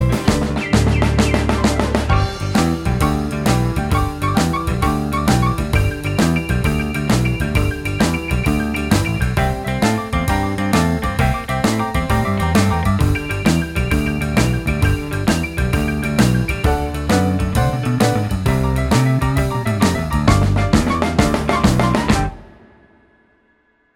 Minus Lead Guitar Rock 'n' Roll 2:44 Buy £1.50